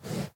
Minecraft Version Minecraft Version 25w18a Latest Release | Latest Snapshot 25w18a / assets / minecraft / sounds / mob / horse / breathe3.ogg Compare With Compare With Latest Release | Latest Snapshot
breathe3.ogg